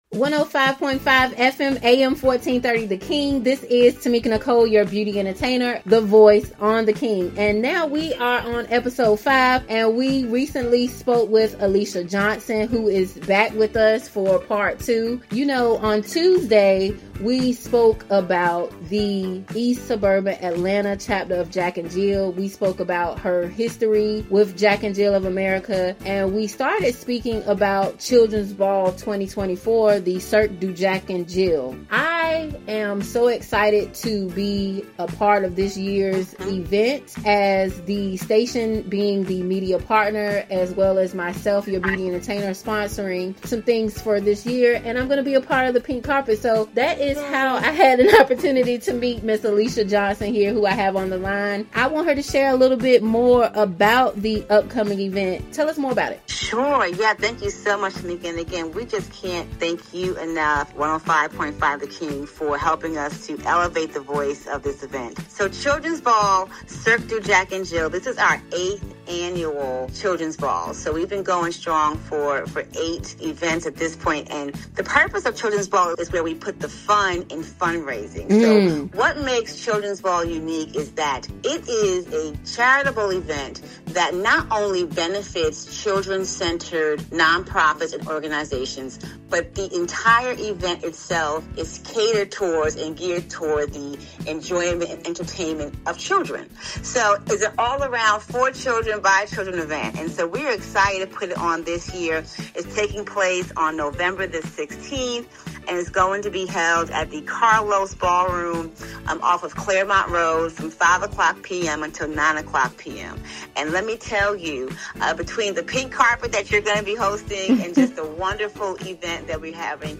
The Voice is a 2 Part Segment Show where local and national leaders share their stories with the world!
Heard on 105.5 FM/AM 1430 & AM 1010 The King